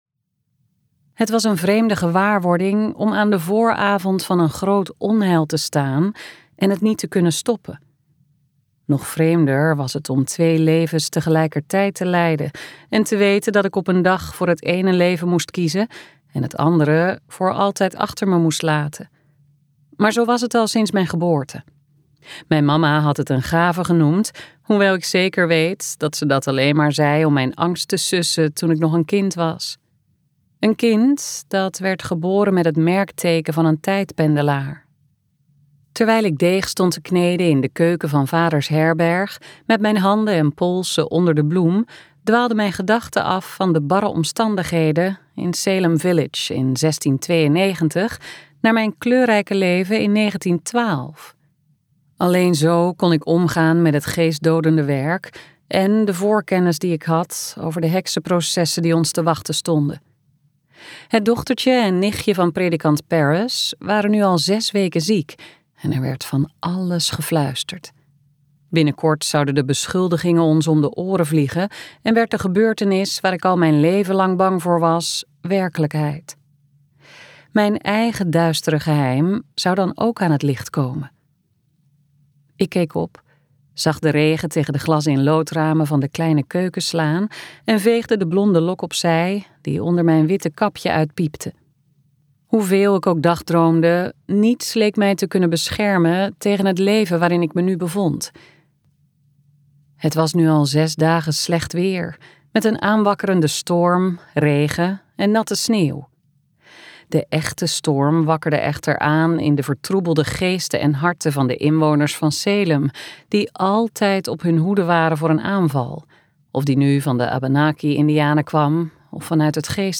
KokBoekencentrum | Tot de tijd ons vindt luisterboek